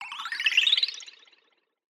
SFX_Magic_Healing_01.wav